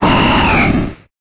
Cri de Barbicha dans Pokémon Épée et Bouclier.